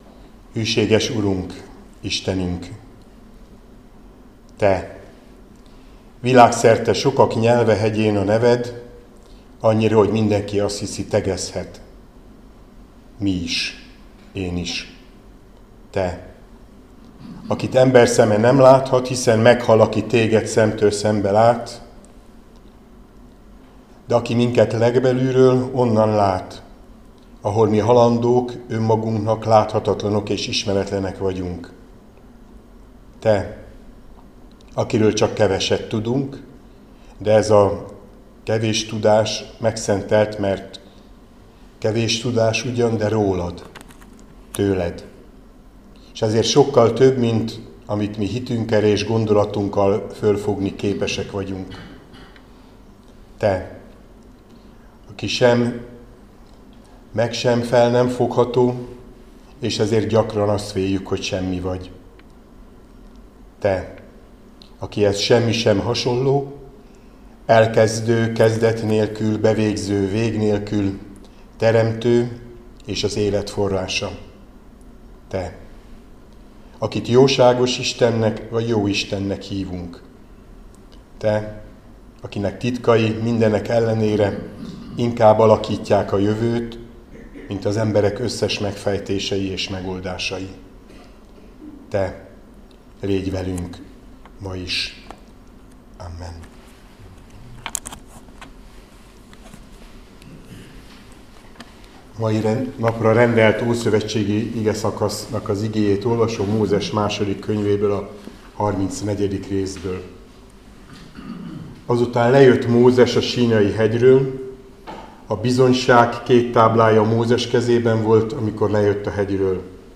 Áhítat, 2025. szeptember 30.
2Móz 34,29-35 Balog Zoltán püspök